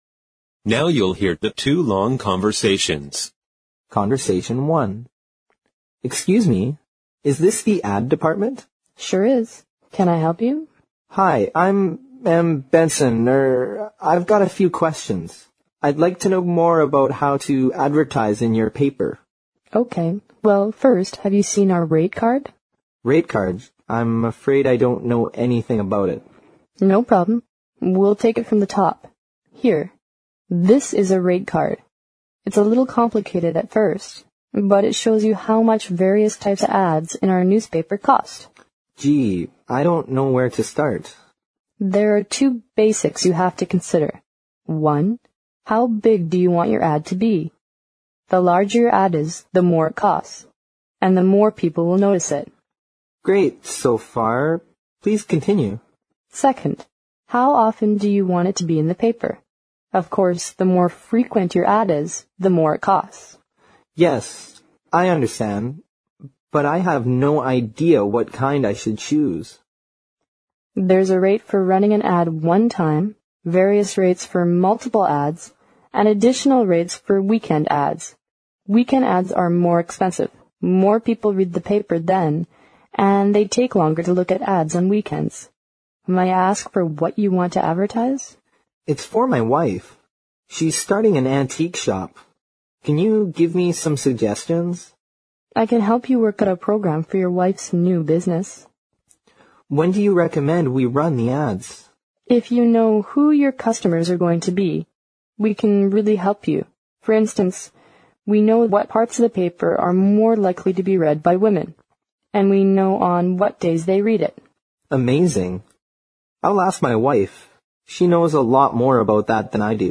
Now you'll hear the two long conversations.